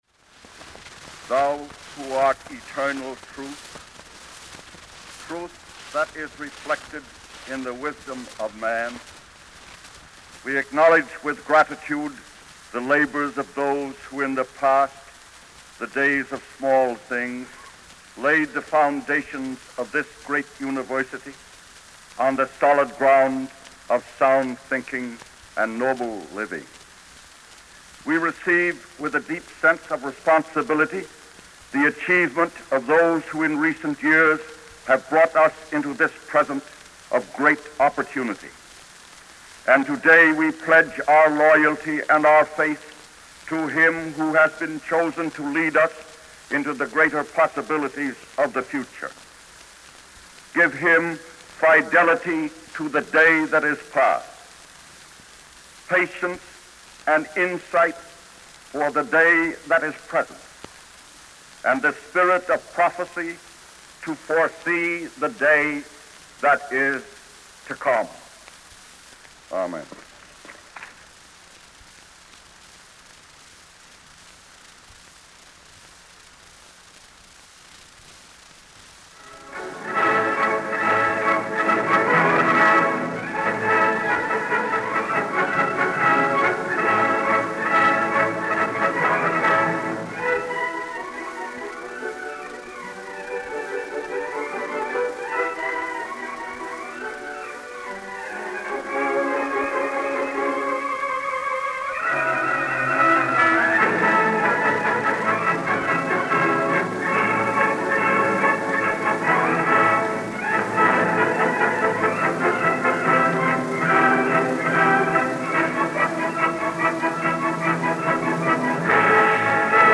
Speeches